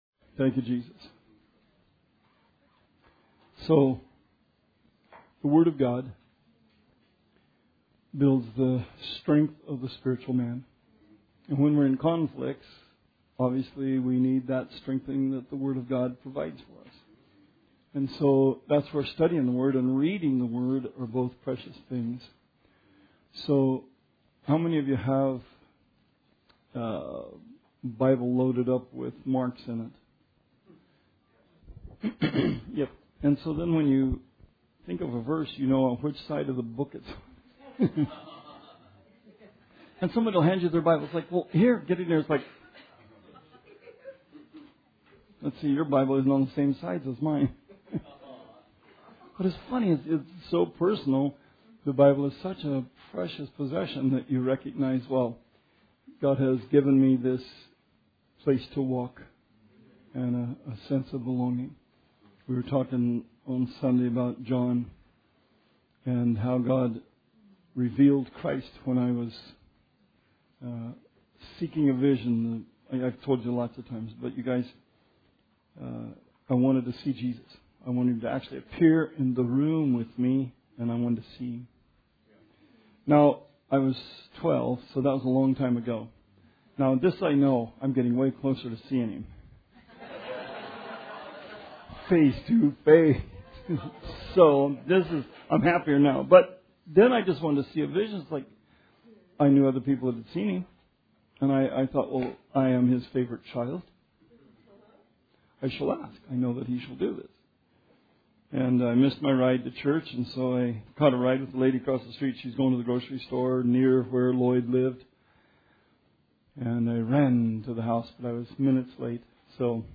Bible Study 6/8/16
Q and A